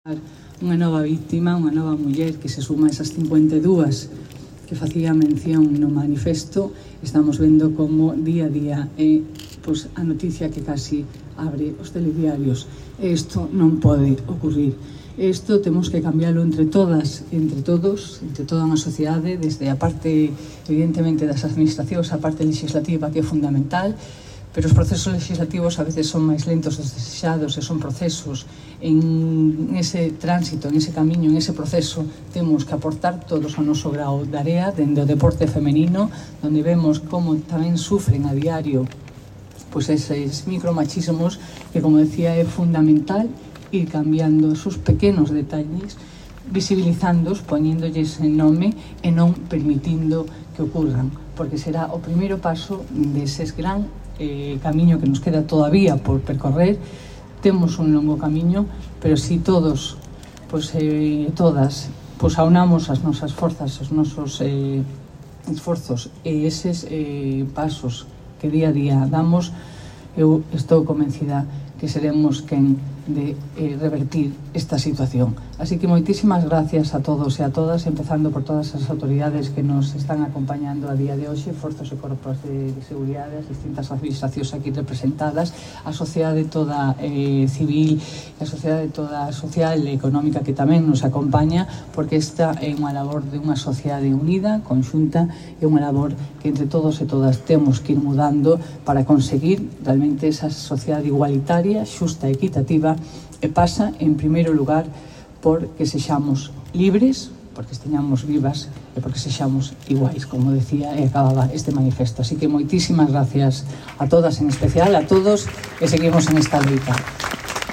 La alcaldesa de Lugo, Lara Méndez, participó esta mañana en el acto de lectura del manifiesto y ofrenda floral con motivo de la celebración del Día Internacional de la Eliminación de la Violencia contra la Mujer que se celebró a las 12 horas frente a la Árbore da vida, en la praza de Santo Domingo.